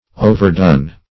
overdone \o`ver*done"\, a.